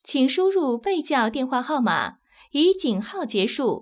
ivr-enter_destination_telephone_number.wav